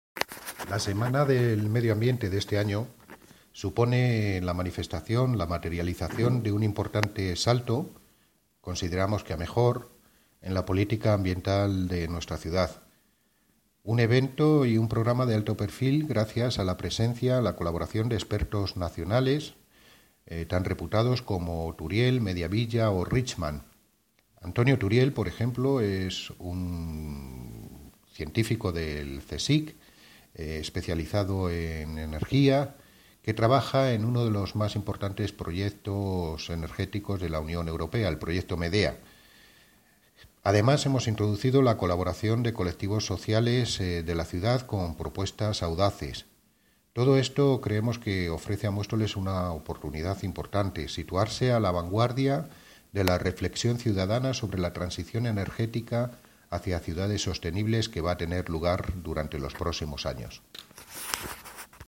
Audio - Miguel Angel Ortega (Concejal de Medio Ambiente, Parques, Jardines y Limpieza Viaria) Semana Medio Ambiente